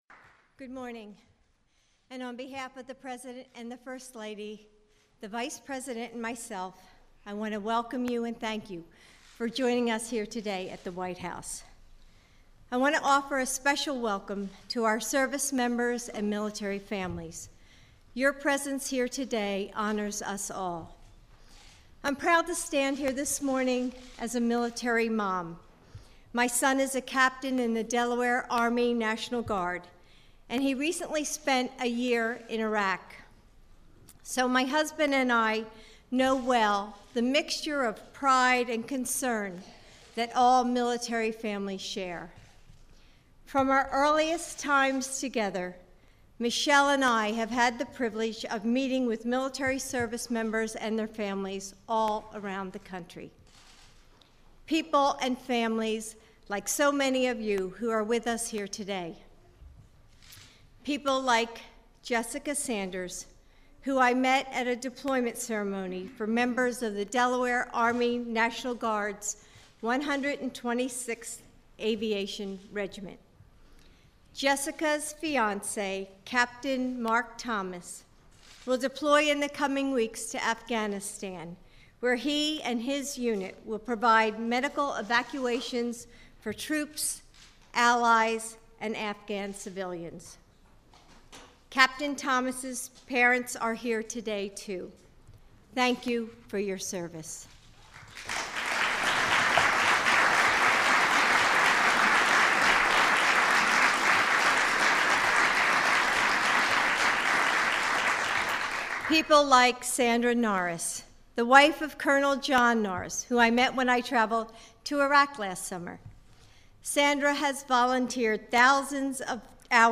U.S. President Barack Obama, First Lady Michelle Obama and Dr. Jill Biden speak to the military families during a ceremony held at the White House East Room